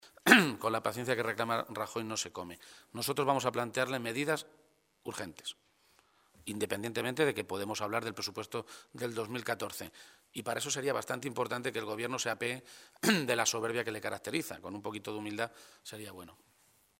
Page se pronunciaba de esta manera esta mañana, en Toledo, a preguntas de los medios de comunicación, en una comparecencia en la que insistía en que, con él, el PP “va a tener siempre una persona proclive a llegar a acuerdos, pero sin engañarnos a nosotros mismos, porque hay cosas en Castilla-La Mancha que no pueden esperar a que negociemos los Presupuestos del año 2014”.
Cortes de audio de la rueda de prensa